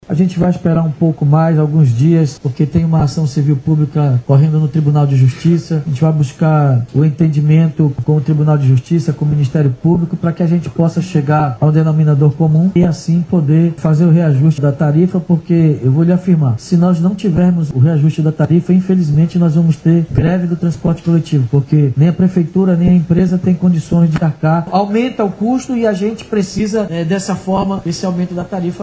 Na ocasião, o prefeito se referiu a ação judicial sobre o aumento no preço da passagem de ônibus, que segue indefinida. Segundo ele, se não houver reajuste na tarifa, há risco de greve do transporte coletivo na cidade, ocasionando prejuízo aos trabalhadores do transporte e usuários.